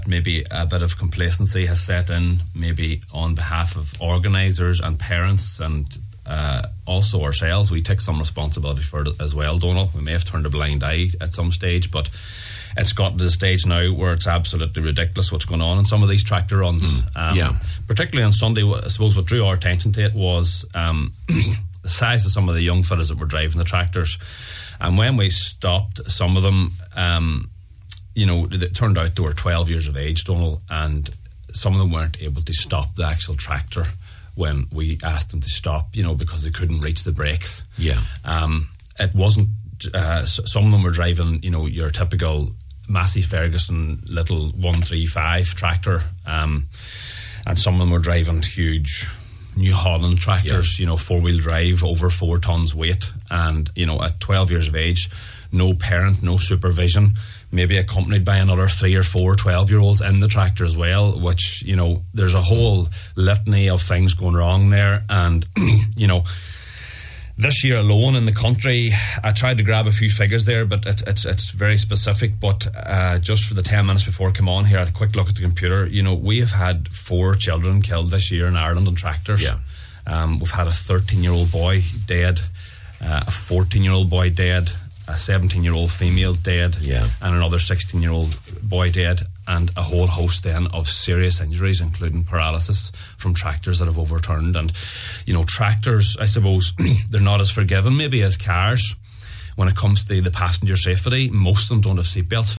on this morning’s Nine ’til Noon Show of the dangers of driving tractors at this age: